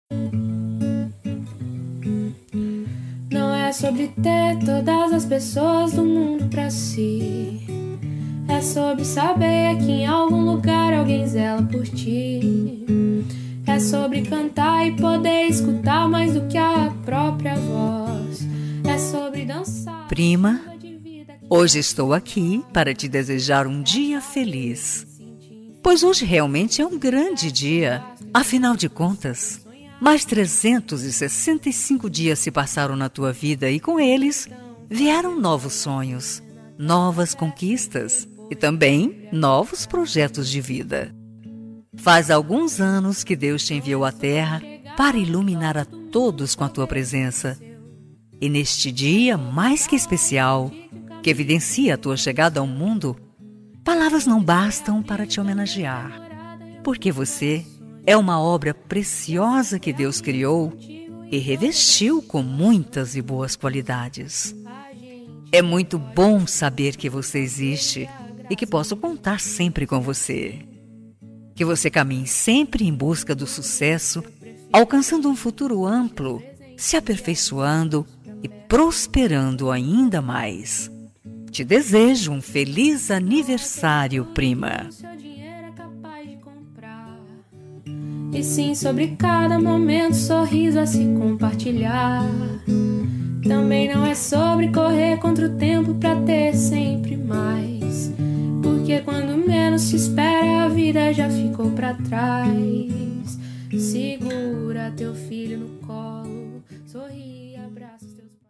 Voz Feminina